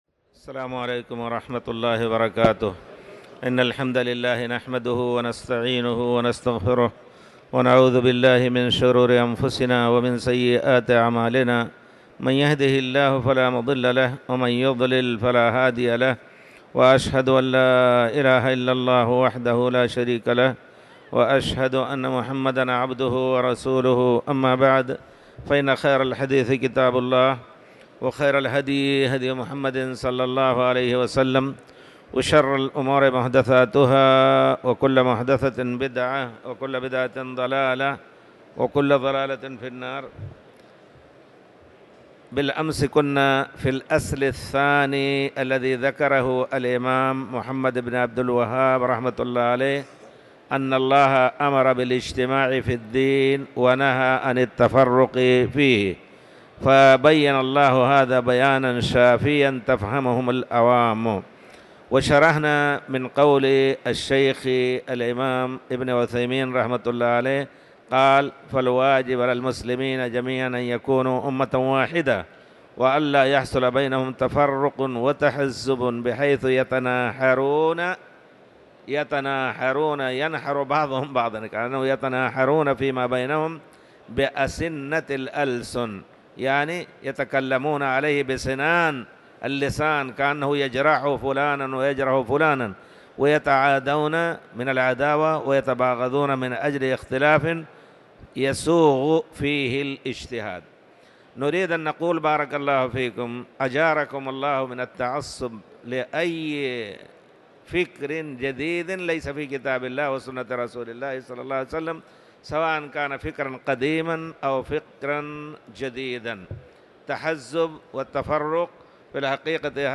تاريخ النشر ٢٣ رمضان ١٤٤٠ هـ المكان: المسجد الحرام الشيخ